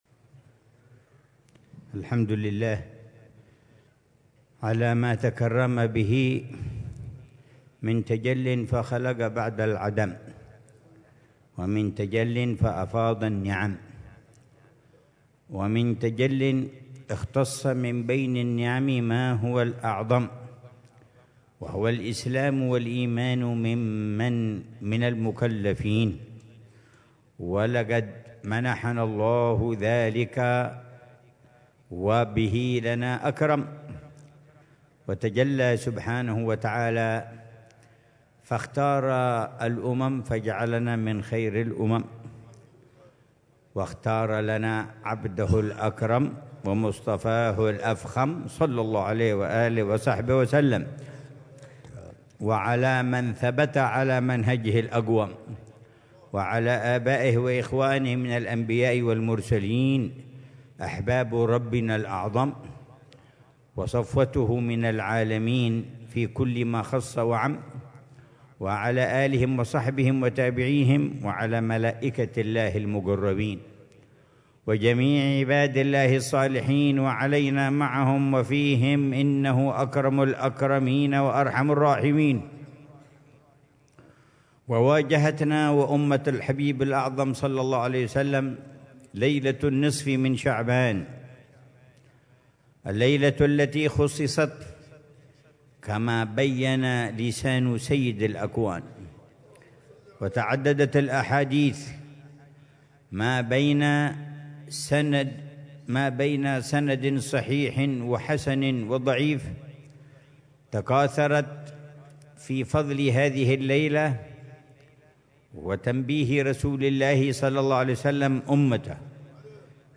مذاكرة
في ليلة النصف من شعبان ( الشعبانية ) في دار المصطفى ليلة الجمعة 15 شعبان 1446هـ